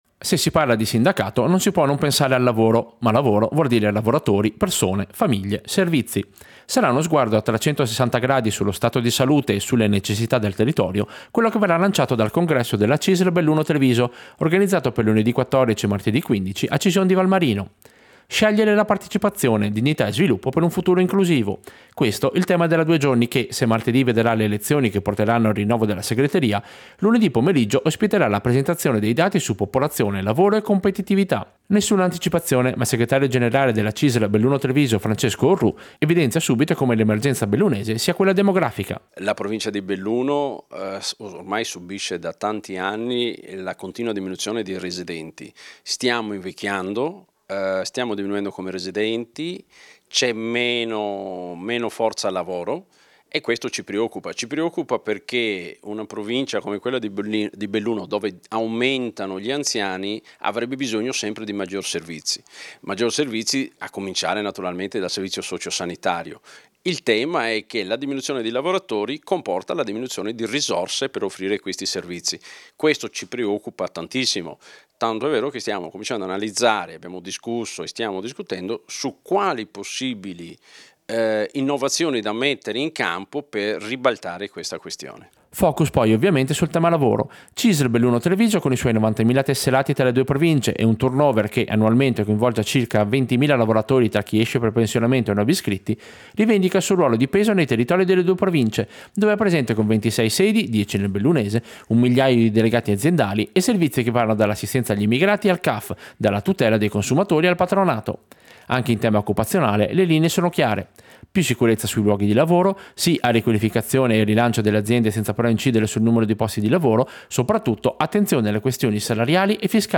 Servizio-Presentazione-congresso-CISL-2025.mp3